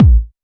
• Natural Disco Kick Drum Single Shot A# Key 611.wav
Royality free bass drum sound tuned to the A# note. Loudest frequency: 143Hz
natural-disco-kick-drum-single-shot-a-sharp-key-611-N9h.wav